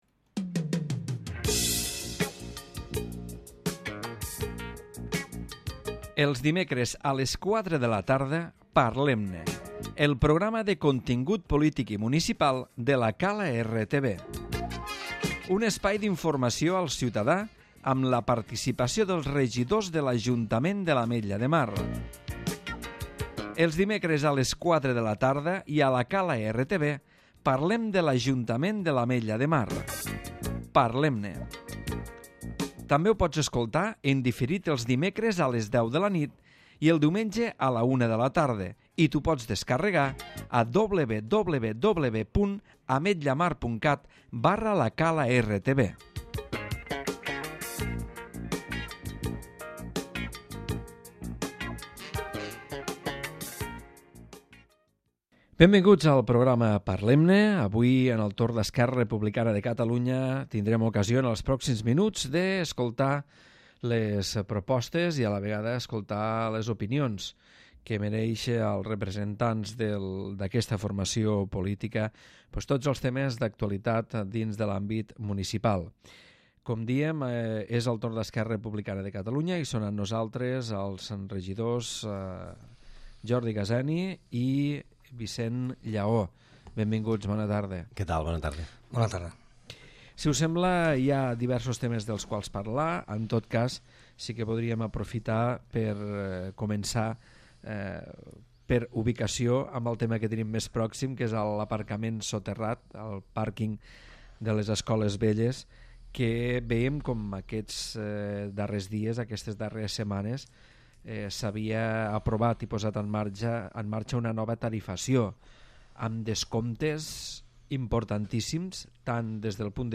Els regidors del Grup Municipal d'ERC, Jordi Gaseni i Vicent Llaó, aborden els temes més actuals de l'àmbit municipal.